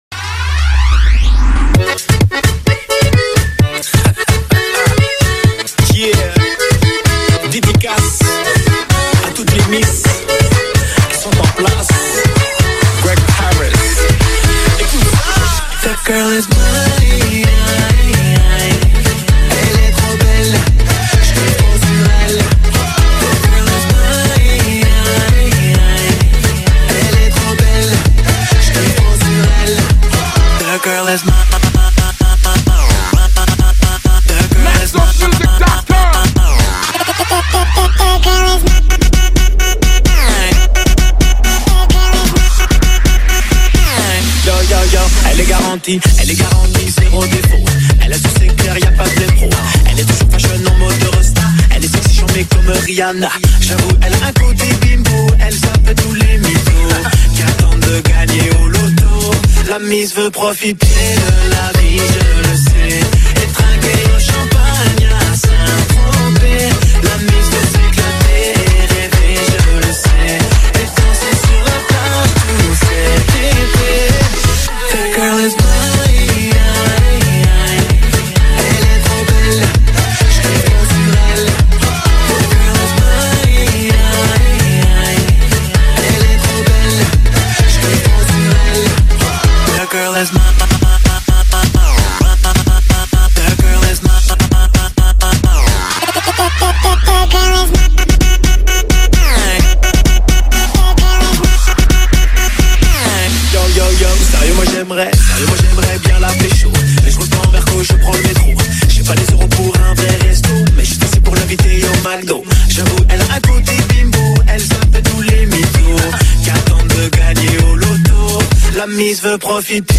Категория: ПОПулярная музыка